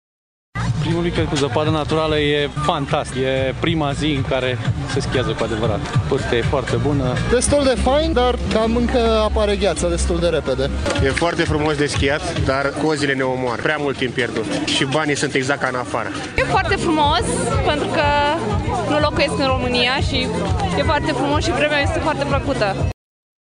stiri-10-febr-voxuri-partii.mp3